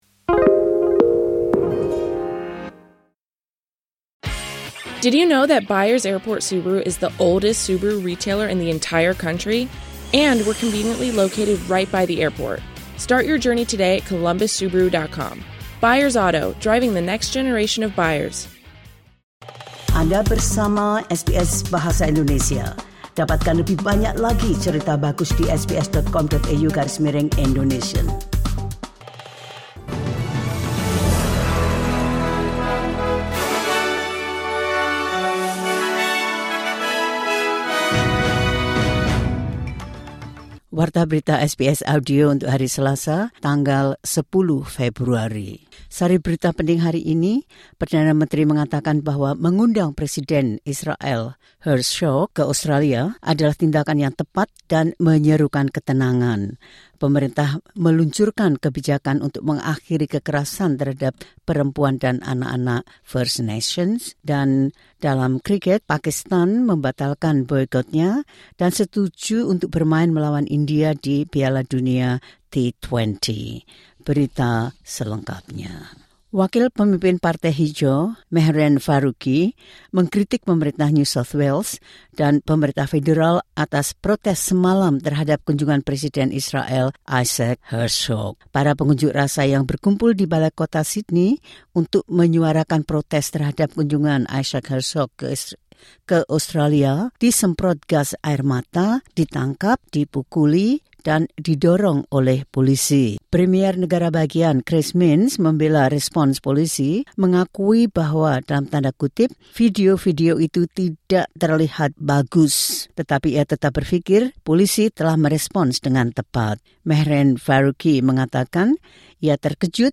The latest news SBS Audio Indonesian Program – Tue 10 February 2026.